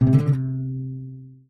guitar_cdc.ogg